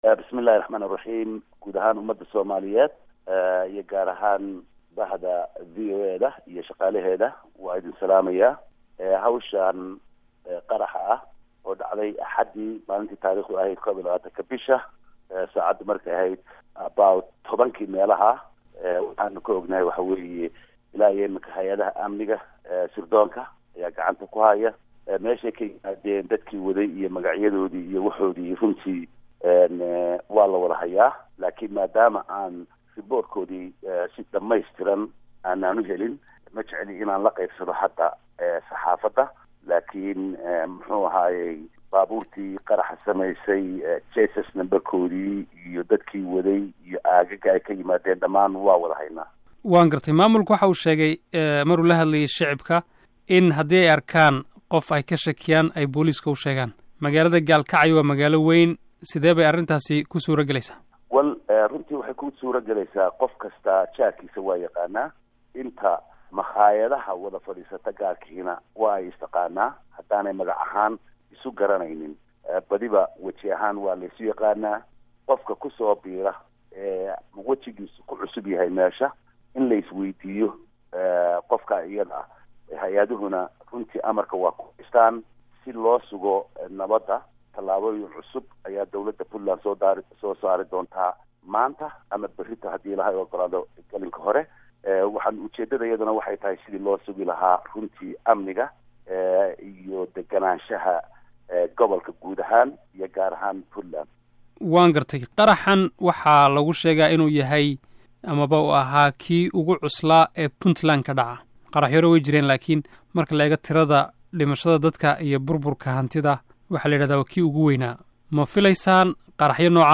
Wareysi: Madaxweyne ku xigeenka Puntland